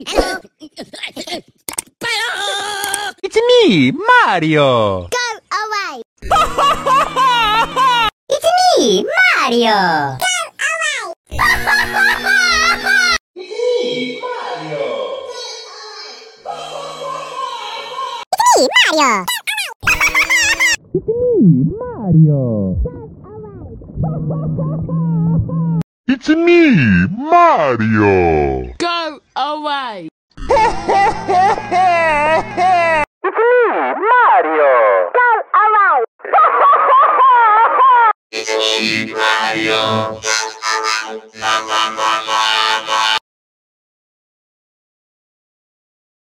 Pocoyo & Mario “Go Away” & Crying “Sound Variations”
Funny sound effects